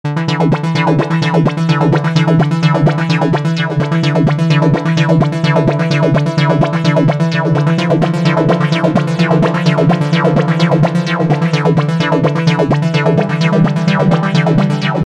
描述：快速的合成器延迟和效果的低音过滤
Tag: 128 bpm House Loops Synth Loops 2.52 MB wav Key : Unknown